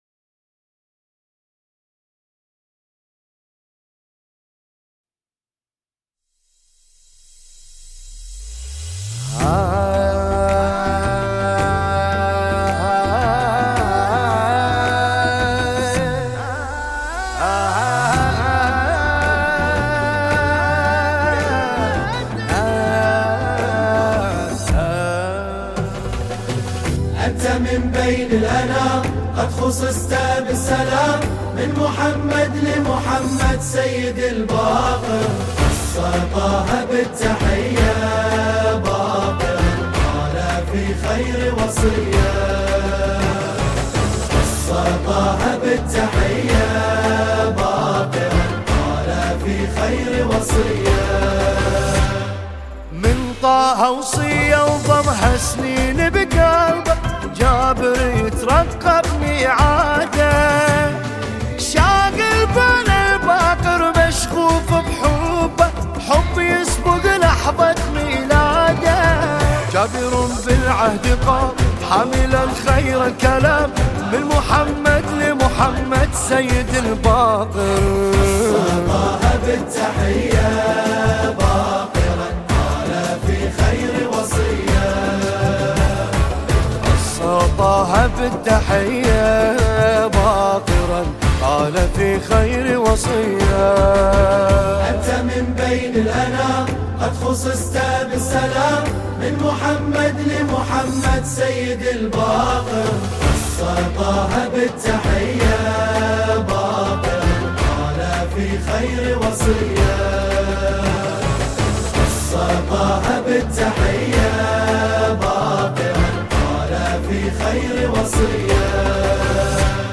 نماهنگ زیبای
مولودی زیبای